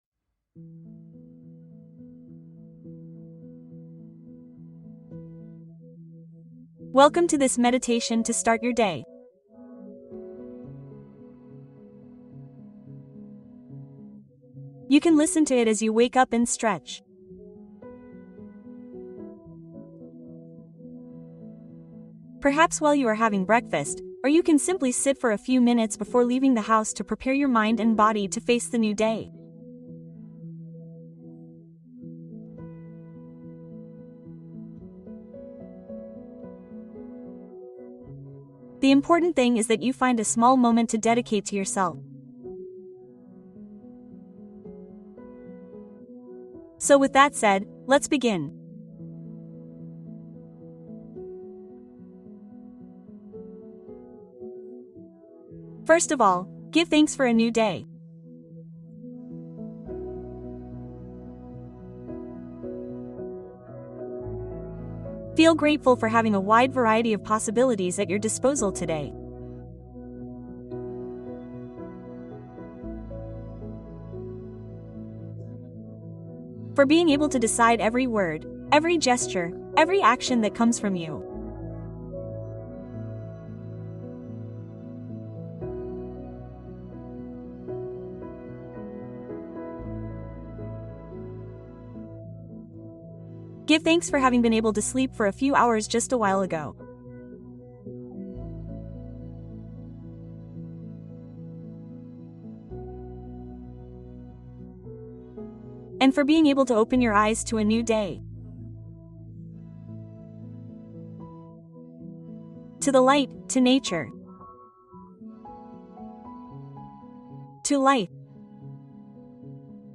Meditación para comenzar el día | Energía positiva para la mañana